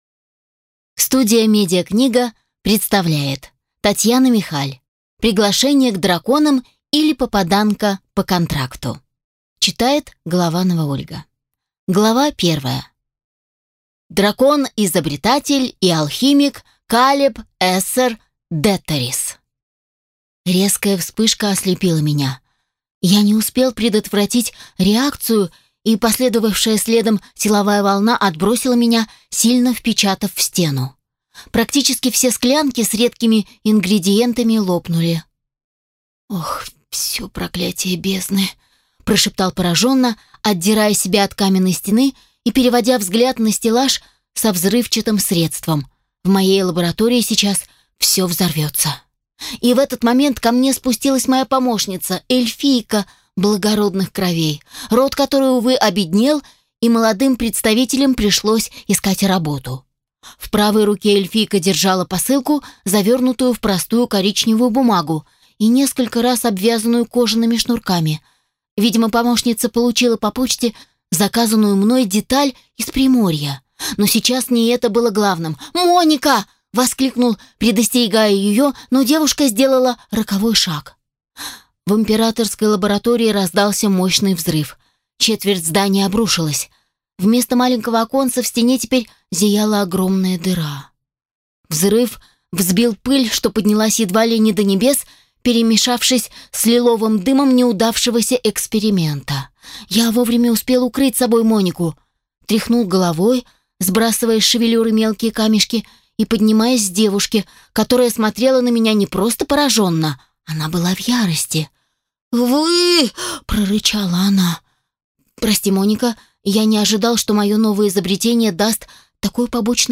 Аудиокнига Приглашение к драконам, или Попаданка по контракту | Библиотека аудиокниг